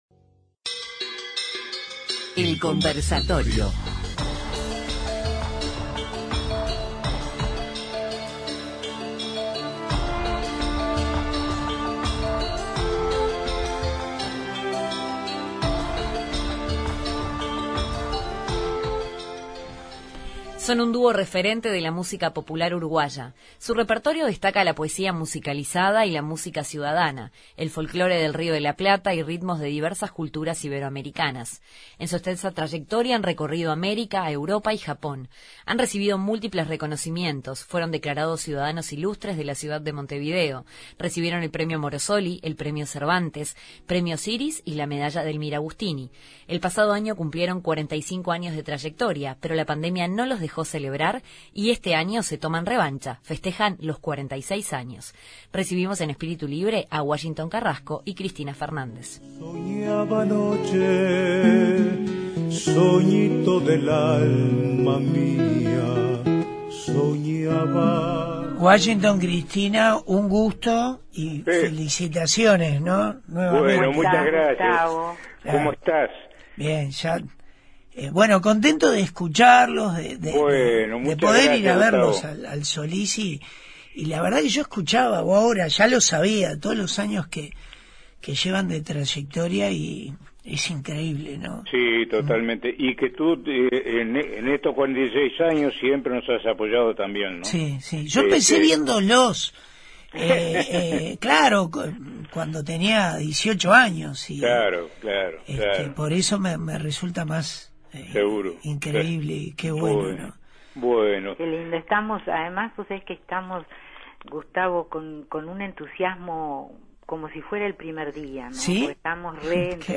Entrevista a Washington Carrasco y Cristina Fernández